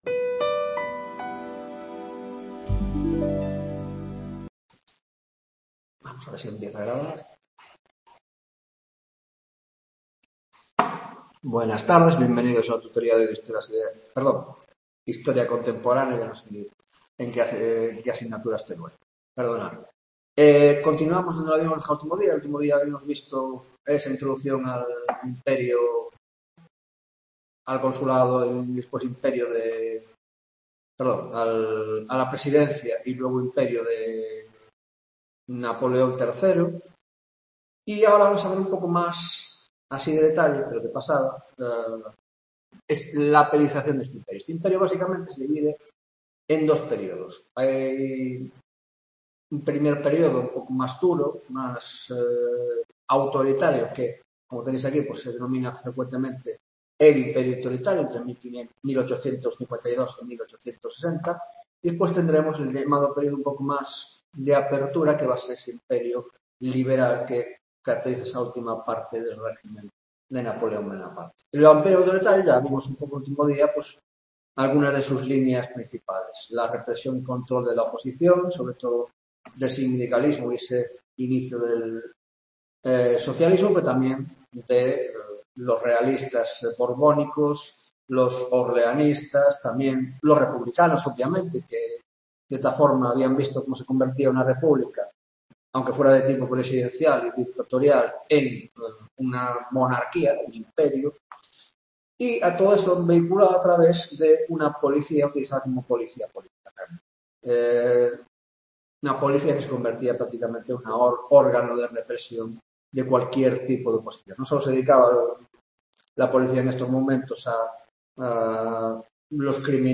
Tutoría de Historia Contemporánea